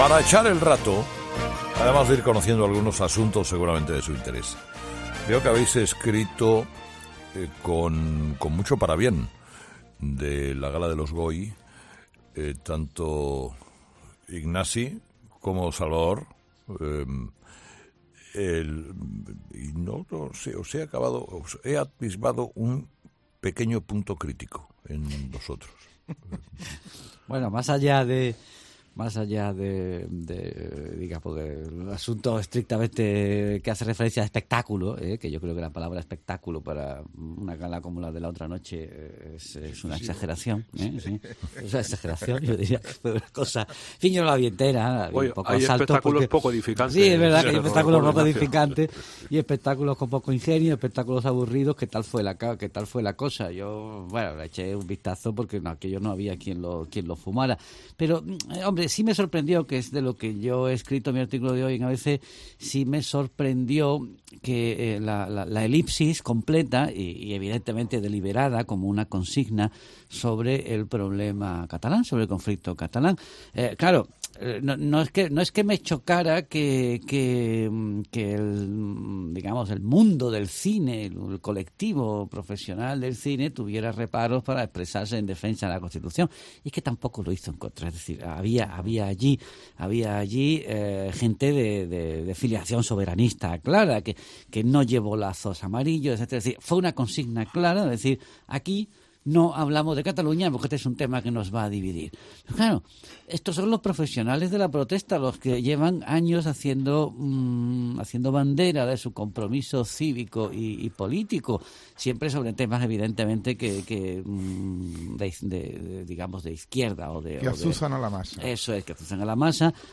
Tertulia sobre la gala de los Goya en 'Herrera en COPE'